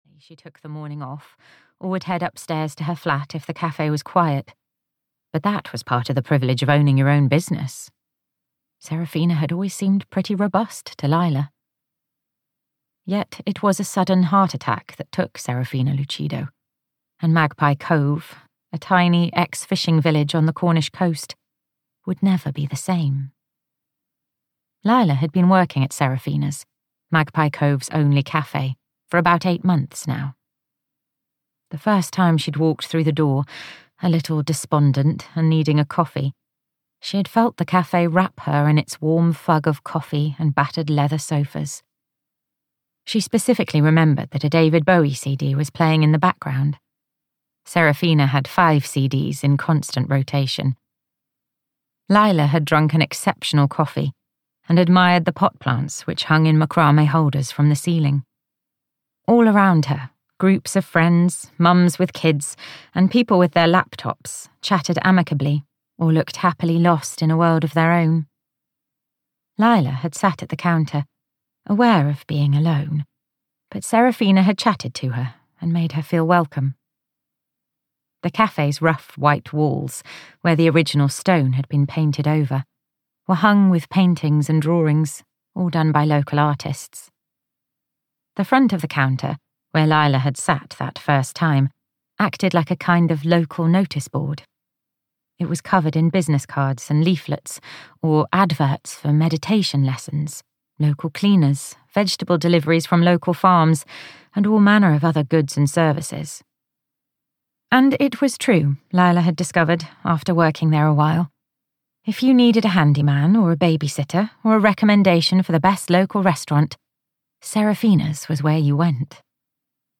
Secrets of Magpie Cove (EN) audiokniha
Ukázka z knihy